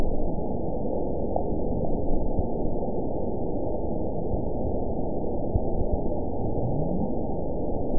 event 919903 date 01/28/24 time 02:34:52 GMT (1 year, 9 months ago) score 9.57 location TSS-AB03 detected by nrw target species NRW annotations +NRW Spectrogram: Frequency (kHz) vs. Time (s) audio not available .wav